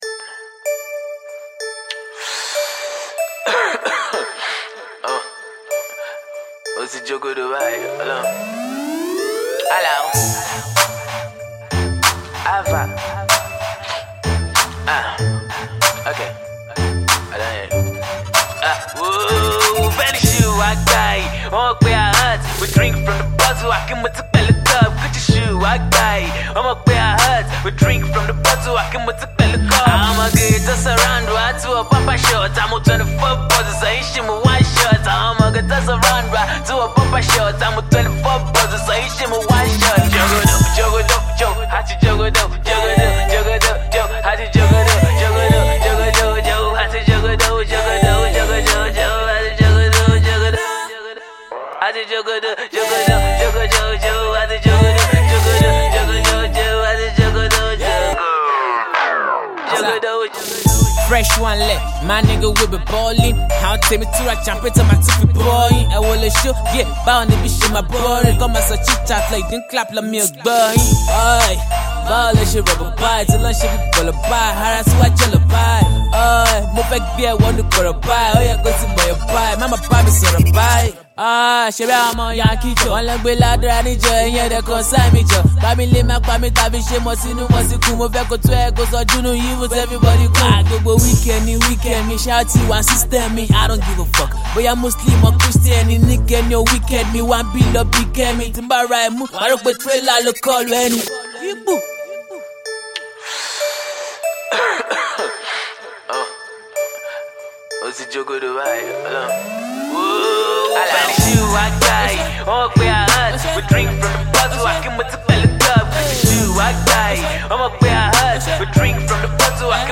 and it's a feel good track worth a listen..